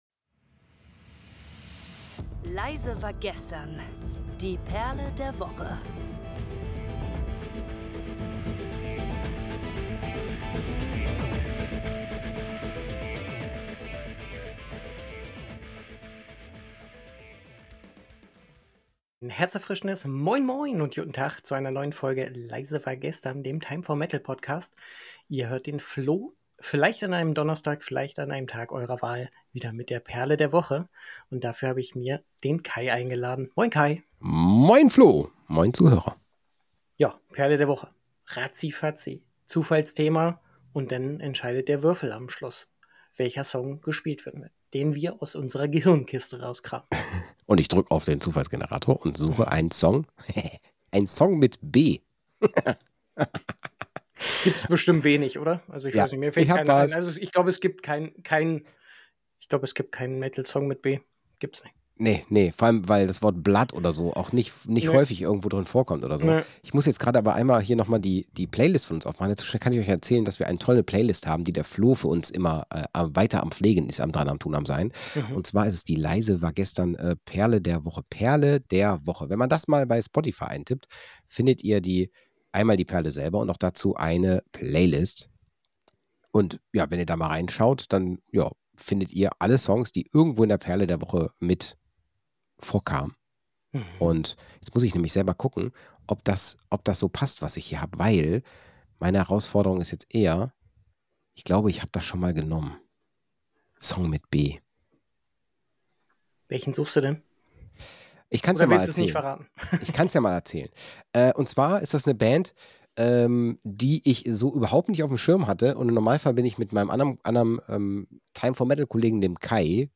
Unsere Moderatoren teilen nicht nur ihre persönlichen Meinungen und Empfehlungen, sondern laden dich auch ein, die regelmäßig aktualisierte Playlist 'Perle der Woche' auf Spotify zu erkunden. 🎧
• Unterschiedliche Vorlieben führen zu spannenden Diskussionen.